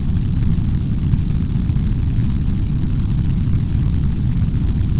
thrusterFire_003.ogg